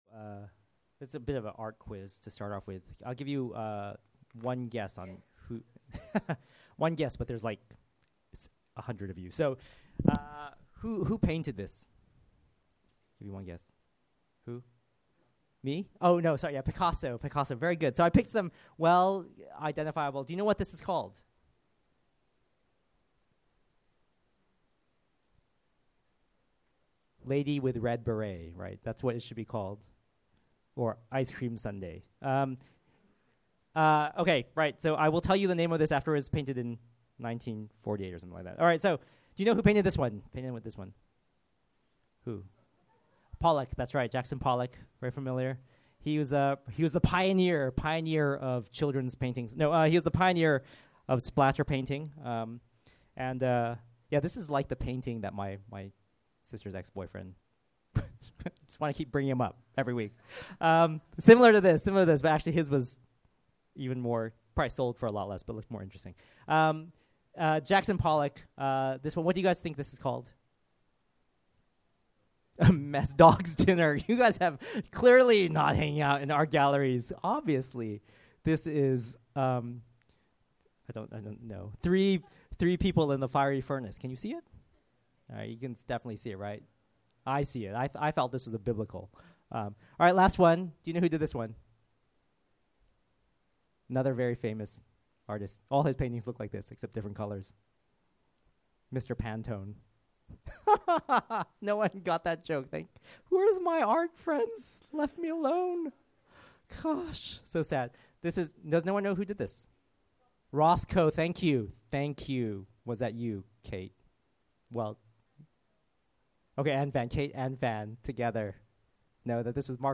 Category Sermon Audio